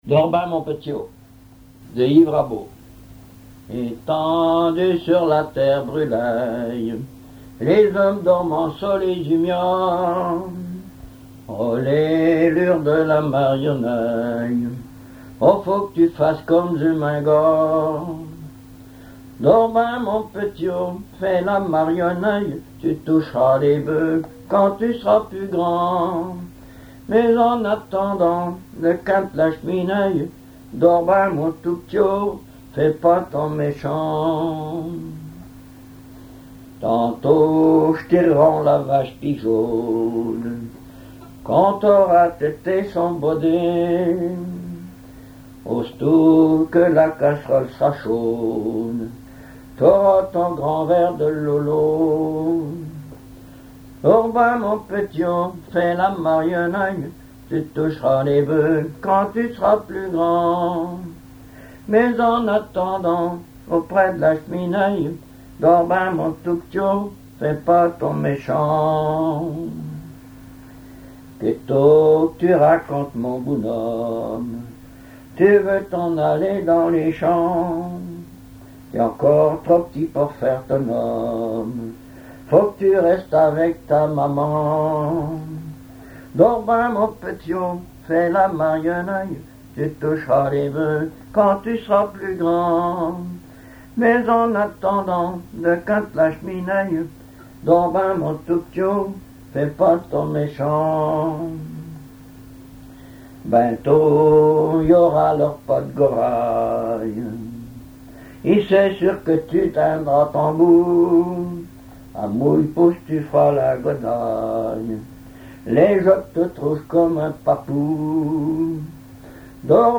bercer, berceuse
chansons populaires et histoires drôles
Pièce musicale inédite